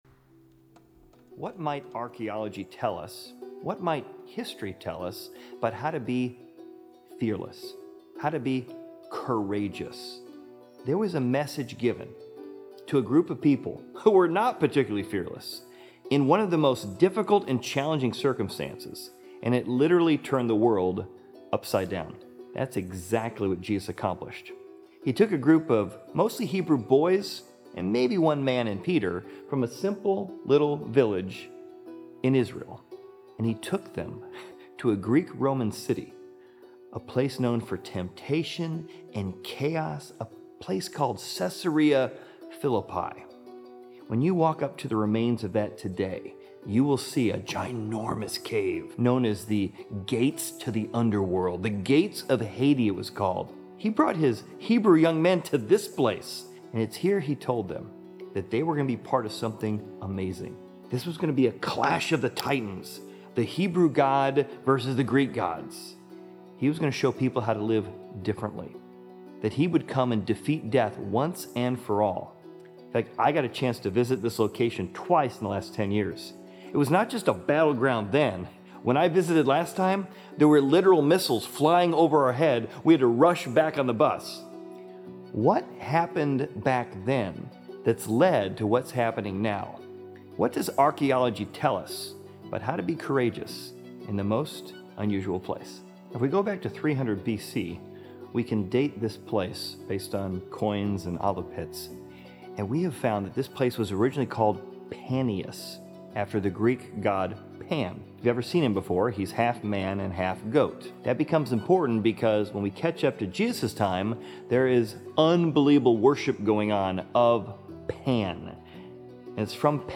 Exploring Service / Raiders of The Lost Cities / Caesarea Philippi